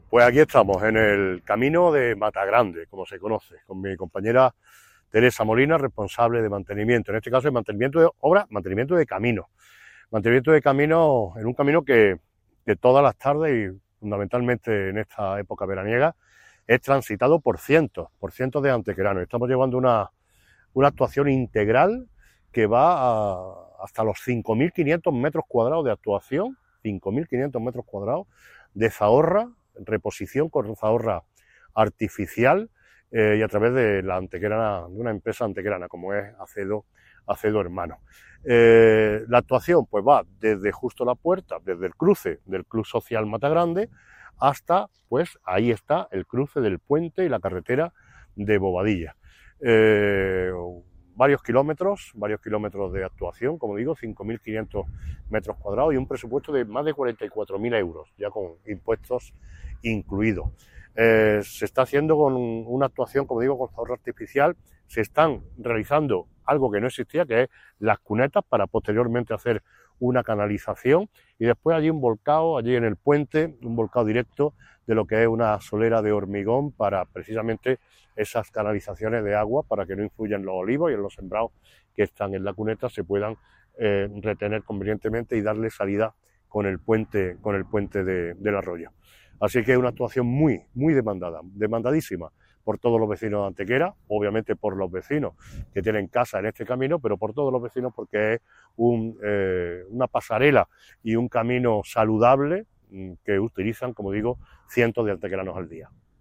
El alcalde de Antequera, Manolo Barón, y la teniente de alcalde delegada de Obras y Mantenimiento, Teresa Molina, han visitado las actuaciones de mejora integral que se están desarrollando en la actualidad en el camino de Matagrande, concretamente entre la intersección con el club social ubicado en la zona y el puente en el que desemboca dicho camino en la carretera de Bobadilla.
Cortes de voz